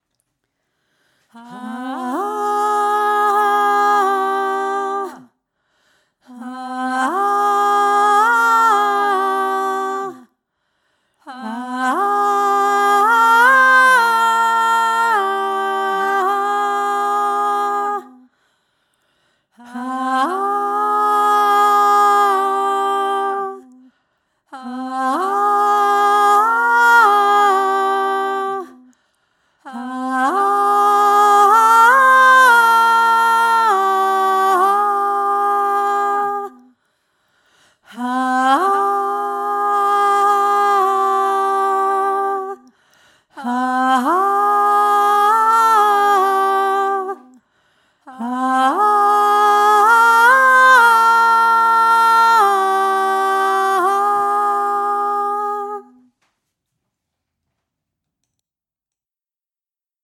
Hohe Stimme
roma-klagelied-hohe-stimme-2.mp3